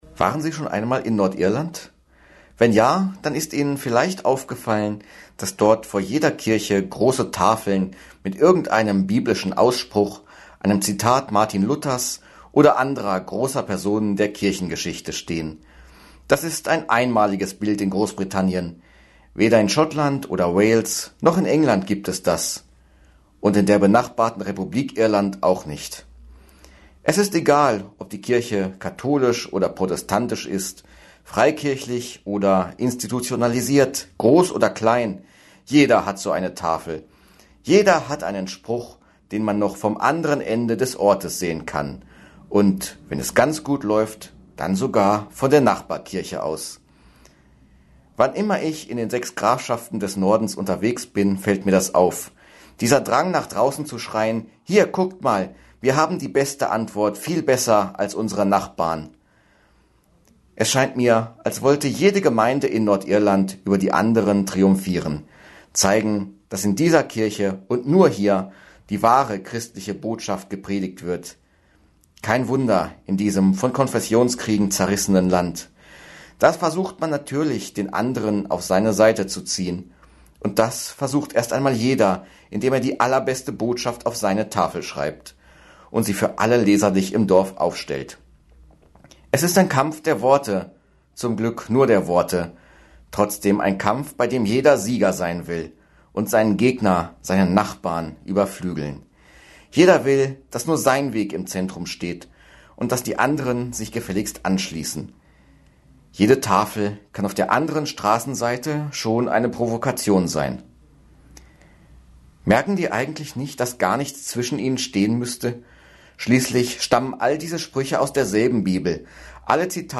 Hameln-Pyrmont: Radioandacht vom 12. Juli 2023